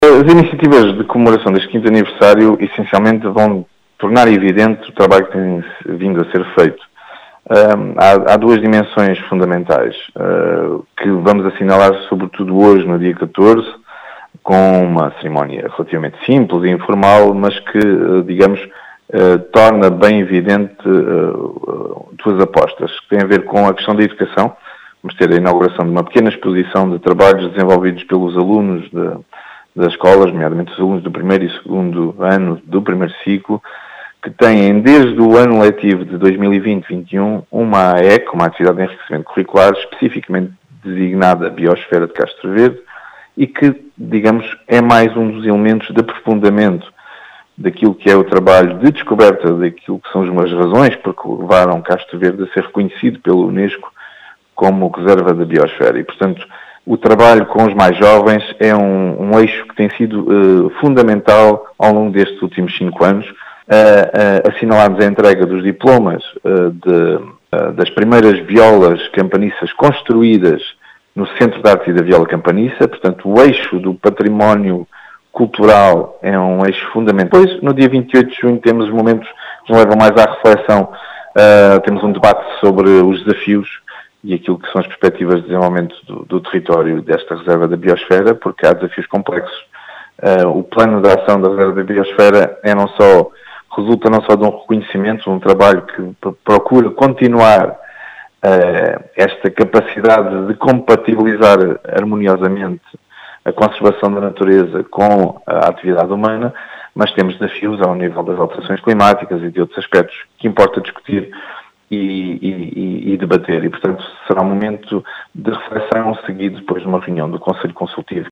As explicações foram deixadas por David Marques, vereador da Câmara Municipal de Castro Verde, que destacou a importância desta comemoração, que pretende “tornar evidente o trabalho que tem sido feito”.